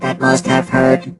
rick_kill_vo_06.ogg